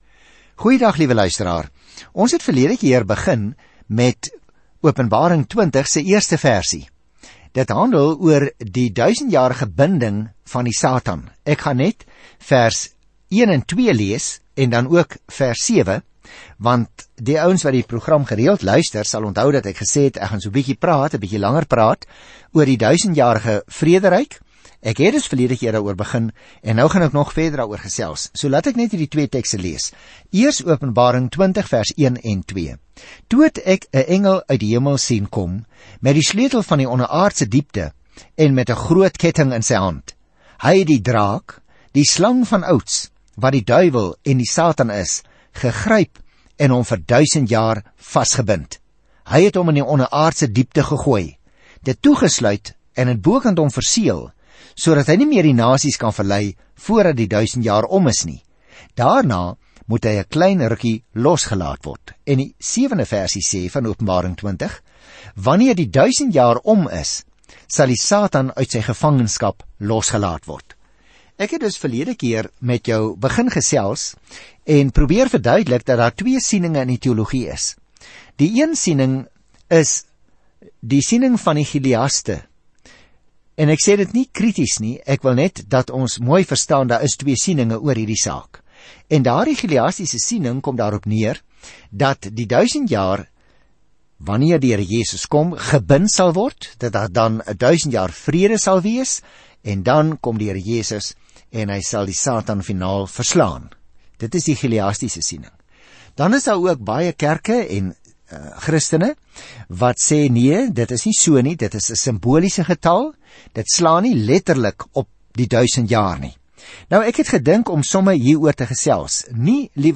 Skrif DIE OPENBARING 20:1-6 Dag 38 Begin met hierdie leesplan Dag 40 Aangaande hierdie leesplan Die Openbaring teken die einde van die ingrypende tydlyn van die geskiedenis aan met die prentjie van hoe boosheid uiteindelik hanteer sal word en die Here Jesus Christus sal regeer in alle gesag, krag, skoonheid en heerlikheid. Reis daagliks deur Openbaring terwyl jy na die oudiostudie luister en uitgesoekte verse uit God se woord lees.